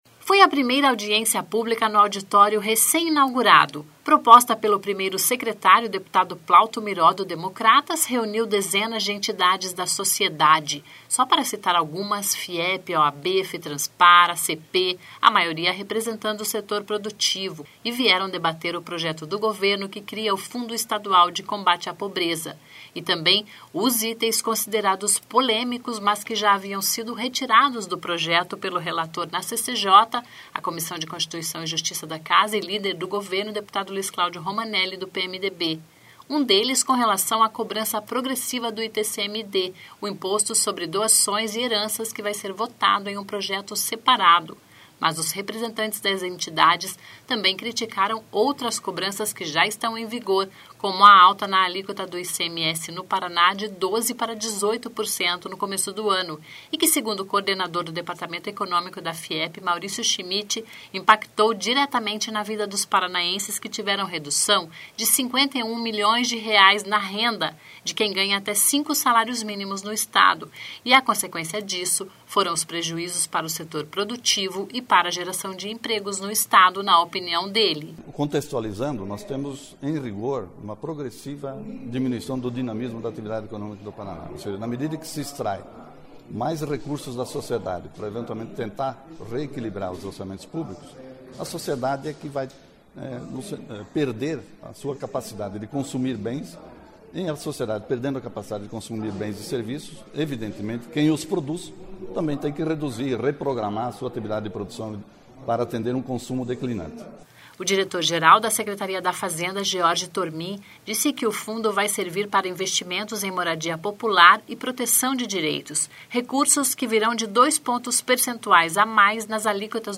(Descrição do áudio))Foi a primeira audiência pública no auditório recém inaugurado.